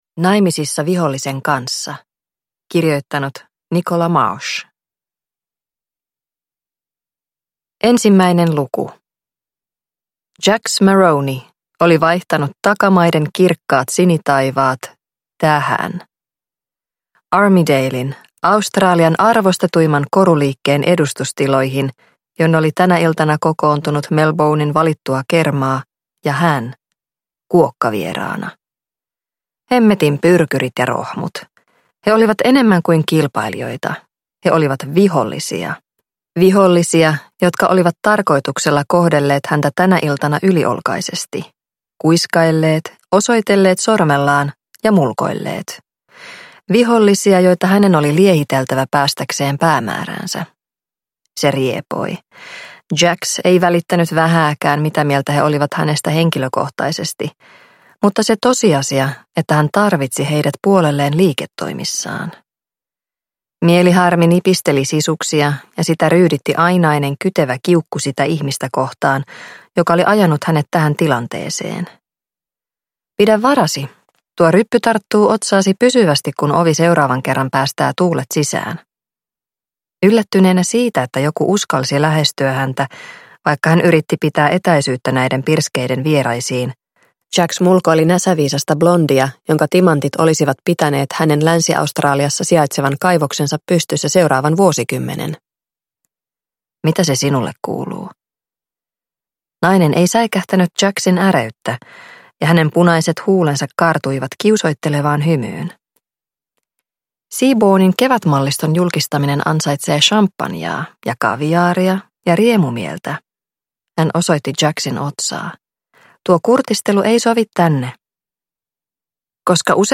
Naimisissa vihollisen kanssa – Ljudbok – Laddas ner